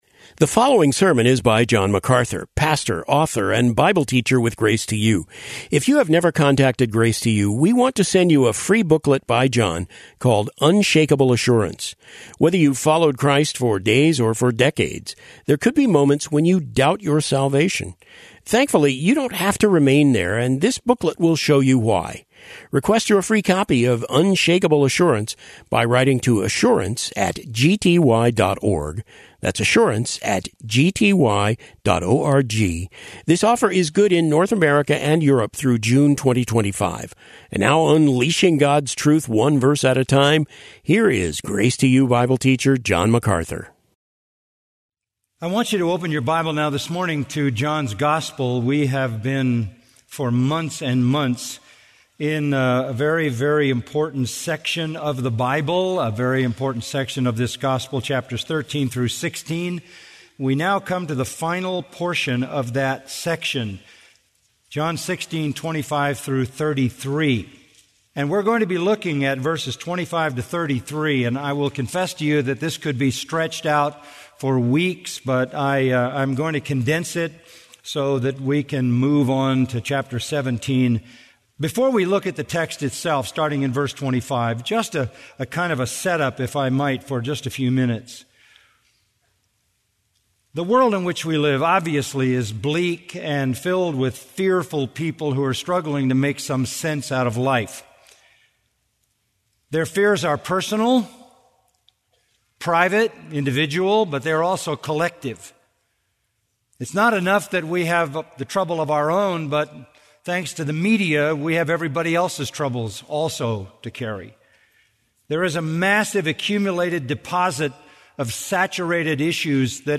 Today’s sermon comes from John MacArthur via Grace To You.